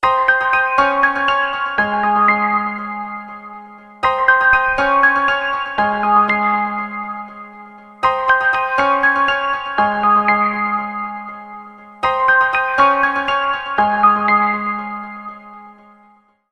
دانلود آهنگ هشدار موبایل 31 از افکت صوتی اشیاء
جلوه های صوتی